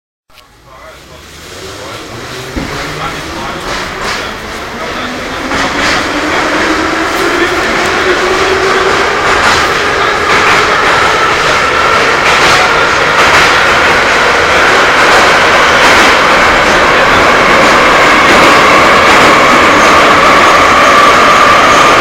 Гул разгоняющегося поезда в метро